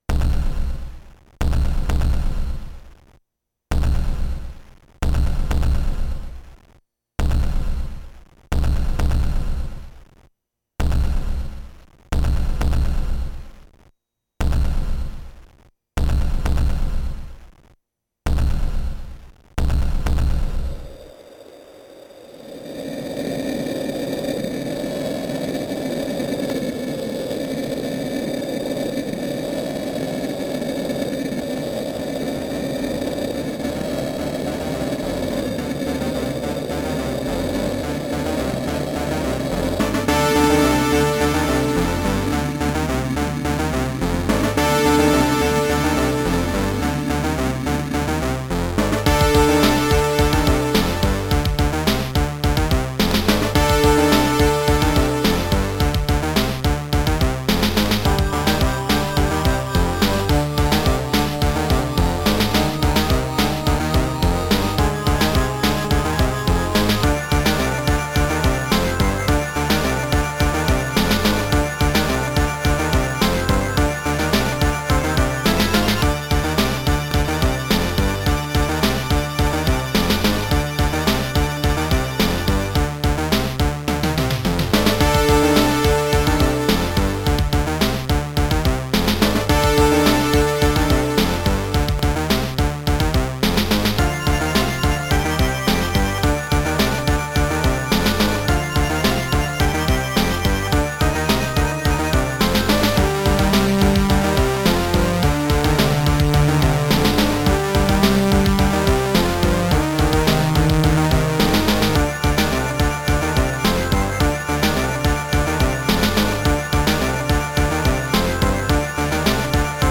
Creative SoundBlaster 16 ct2740
* Some records contain clicks.